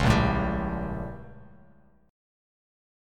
A#mM13 chord